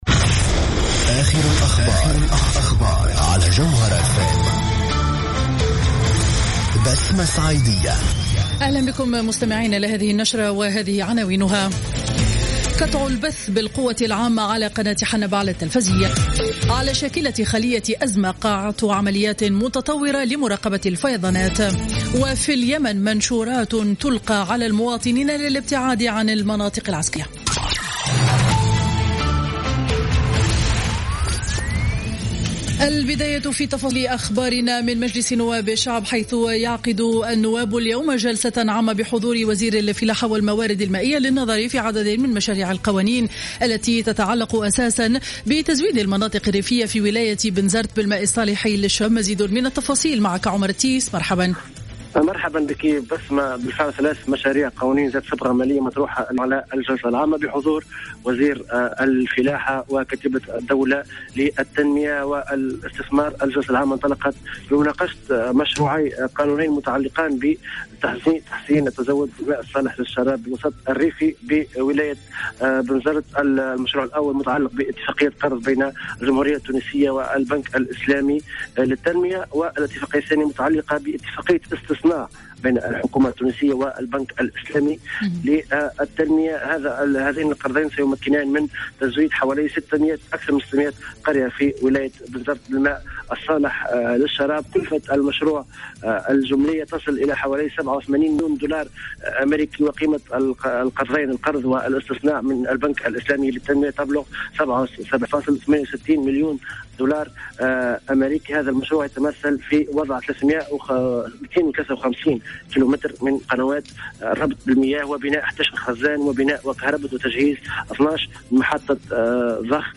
نشرة أخبار منتصف النهار ليوم الخميس 01 أكتوبر 2015